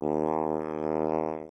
voice lines
OOooOooOOO Drug fiend.wav